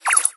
蝙蝠Bats是常见的空军卡牌， 音效都类似于蝙蝠叫声。
部署音效
CR_bat_deploy_01.mp3